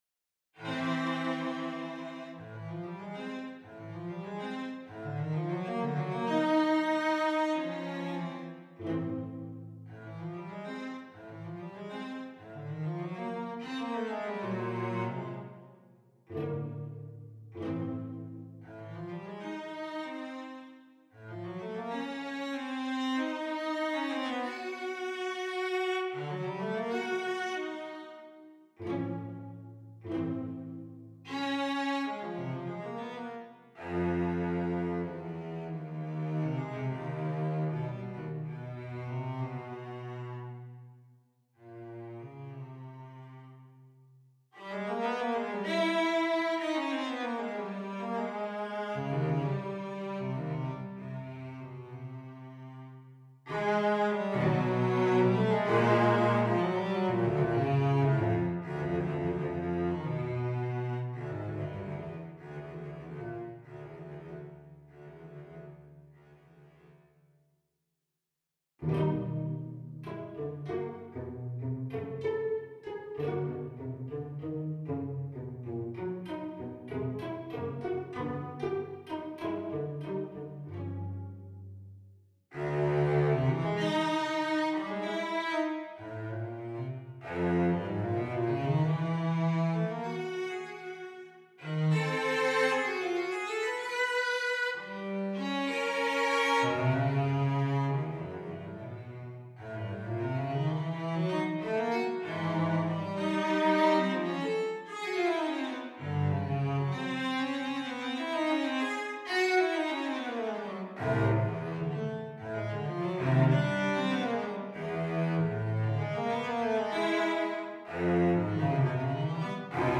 for solo cello alone 5 Mins.
encore piece with its lively and energetic character